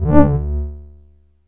bass 5.71.wav